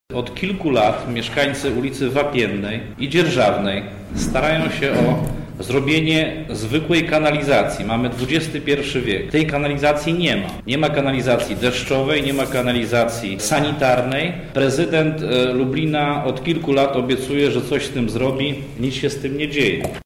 – mówi Piotr Breś, lubelski radny Prawa i Sprawiedliwości: